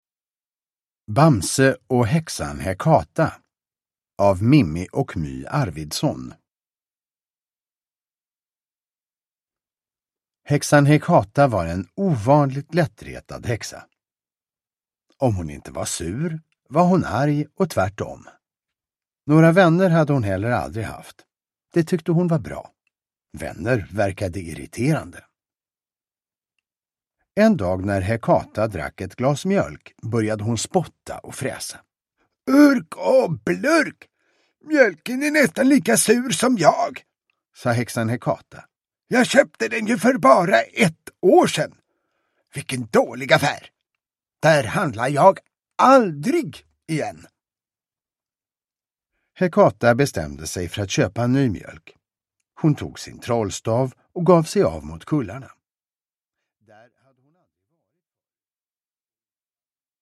Bamse och Häxan Häkata – Ljudbok